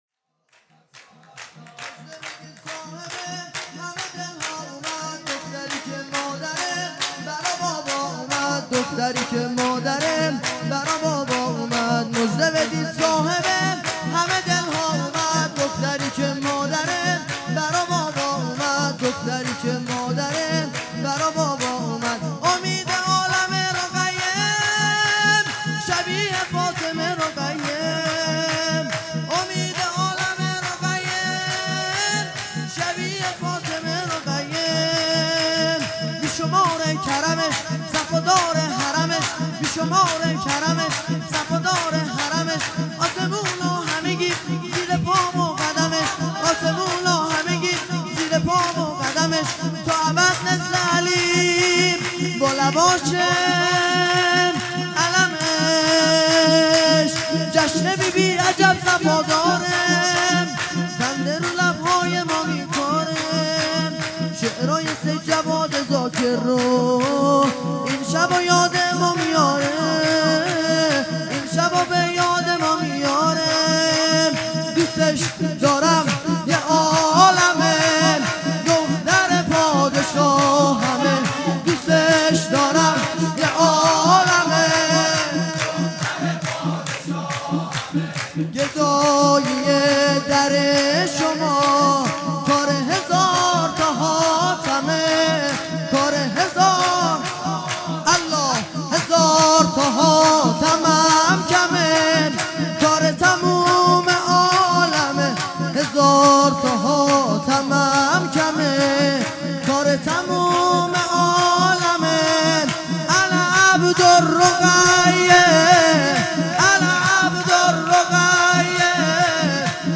هیئت دانشجویی فاطمیون دانشگاه یزد
سرود
ولادت حضرت رقیه (س) | ۱۰ اردیبهشت ۱۳۹۸